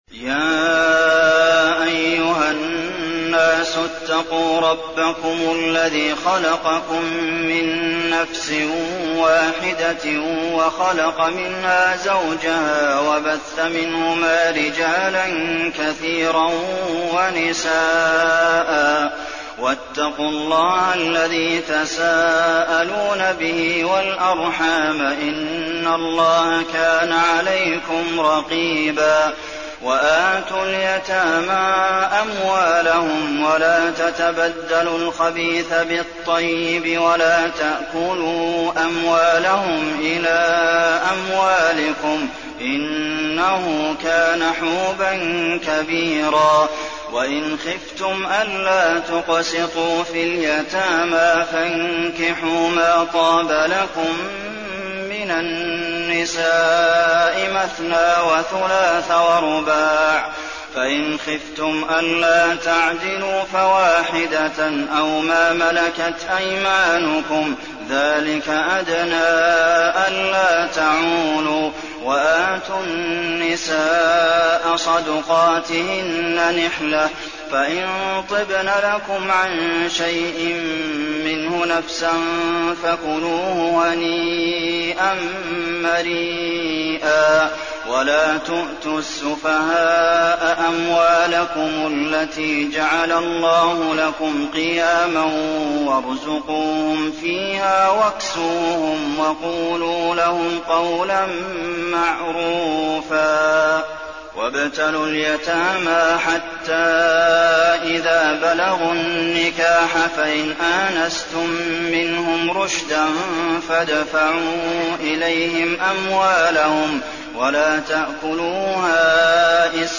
المكان: المسجد النبوي النساء